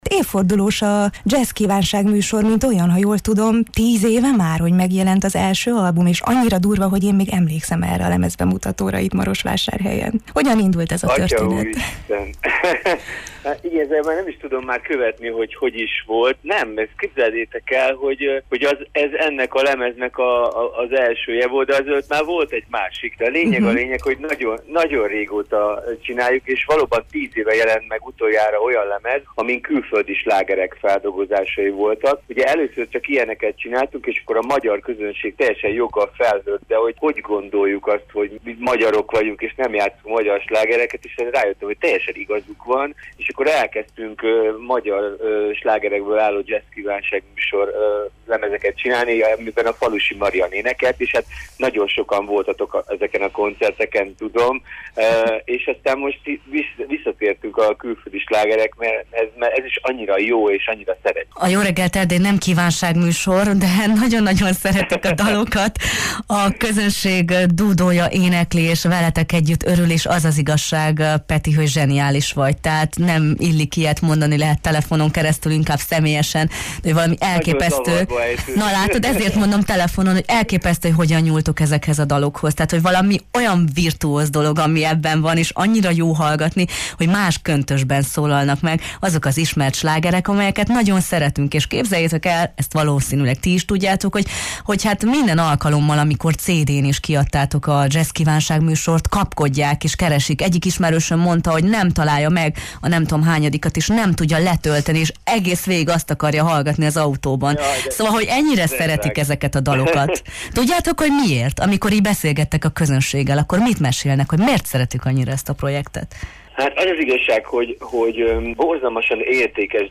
zongoristával beszélgettünk a Jó reggelt, Erdély!-ben